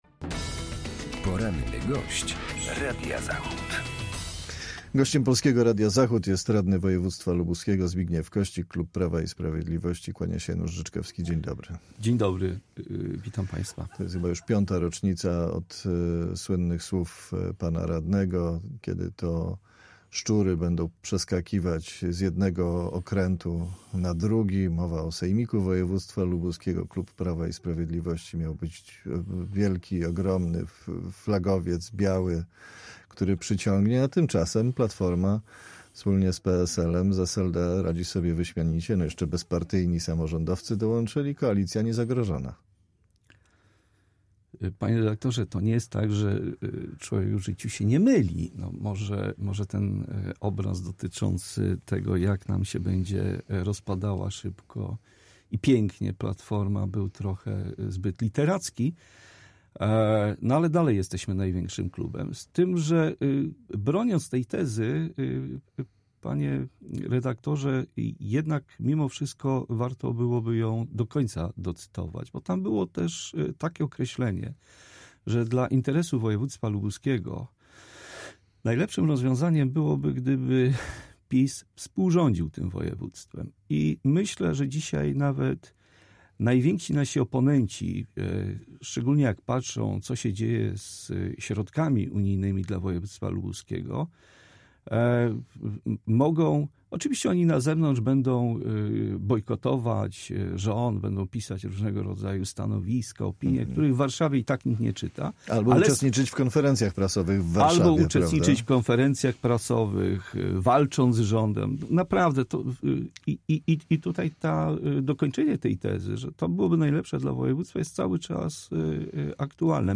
Z radnym Sejmiku Województwa Lubuskiego, klub Prawa i Sprawiedliwości rozmawiał